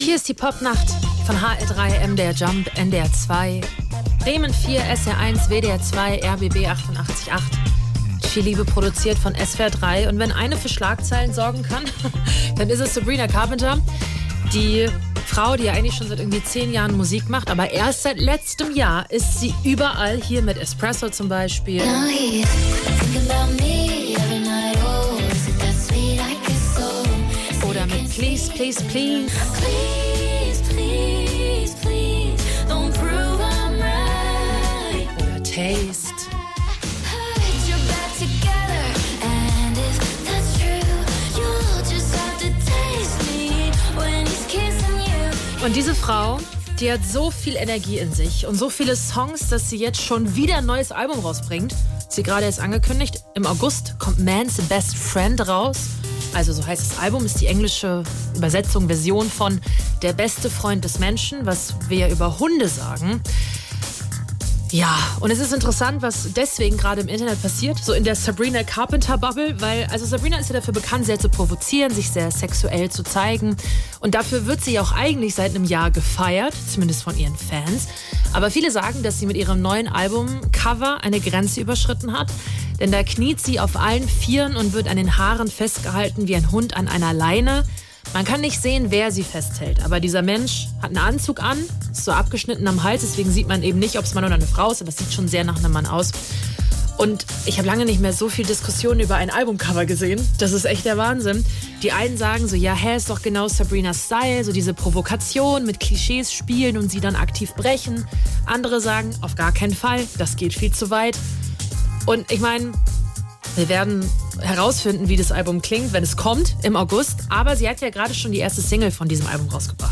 Hier ihr Urteil und die neue Carpenter-Single.